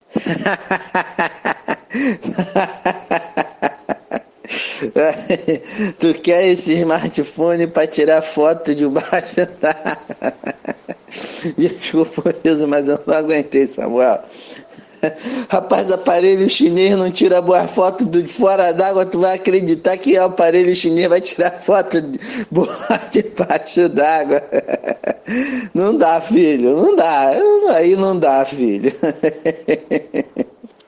Risada Irônica